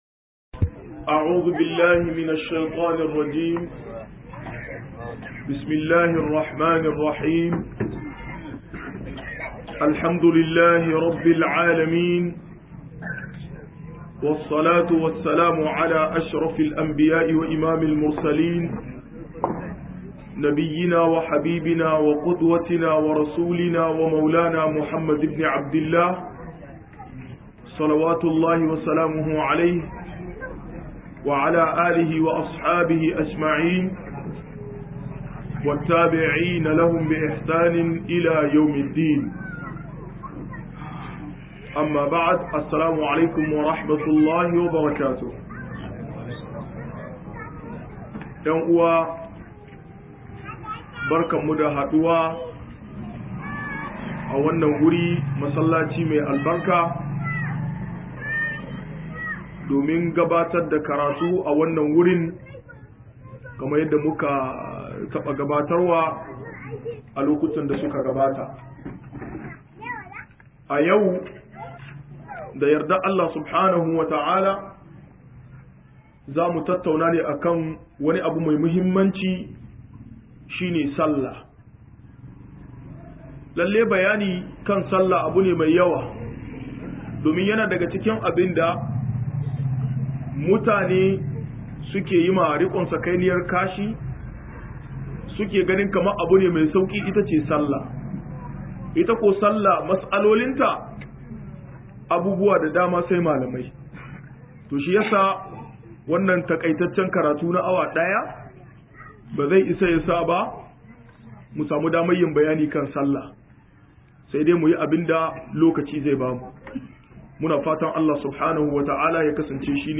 47-sallah - MUHADARA